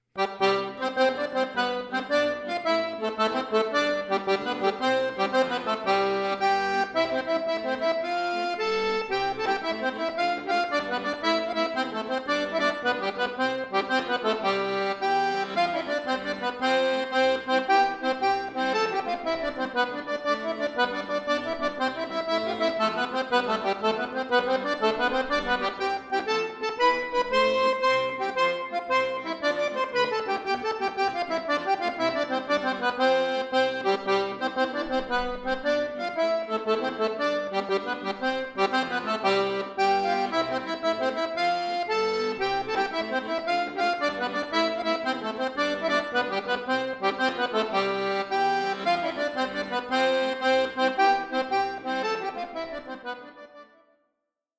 Folk
Irish